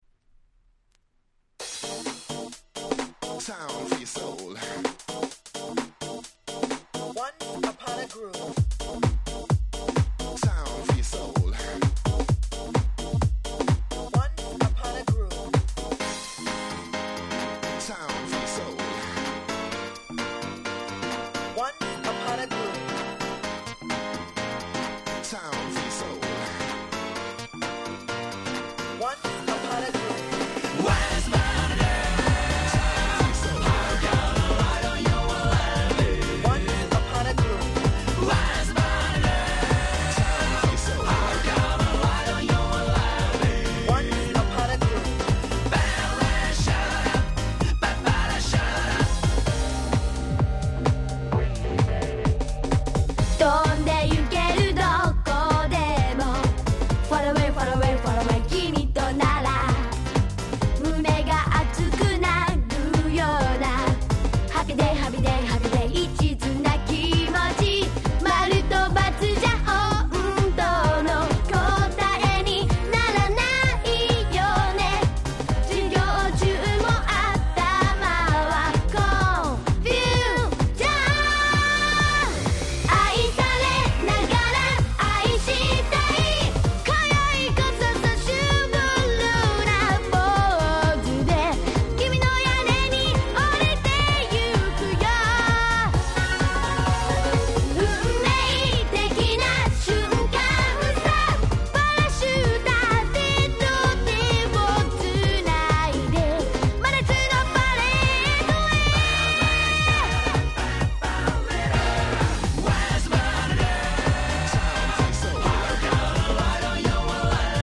98' Big Hit J-Pop/R&B !!